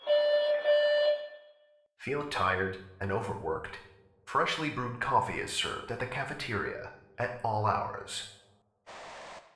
announcement6.ogg